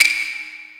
normal-hitwhistle.wav